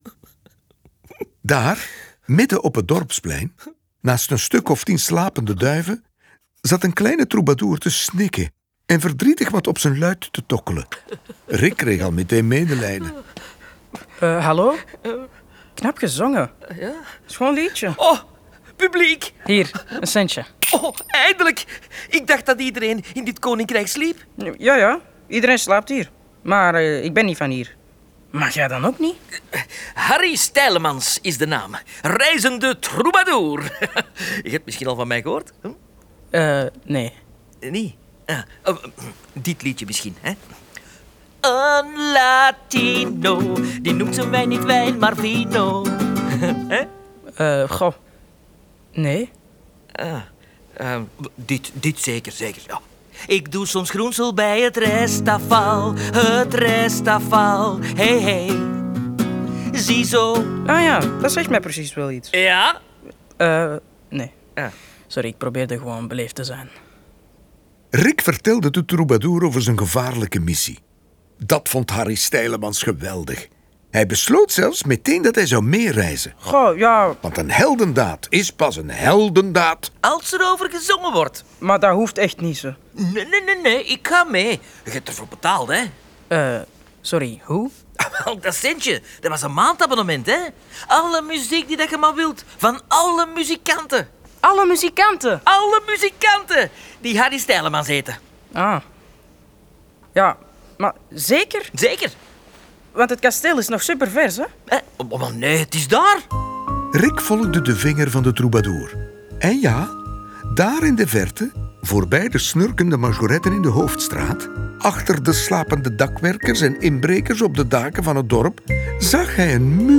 Tekst (hoorspel en boek): Koen Van Deun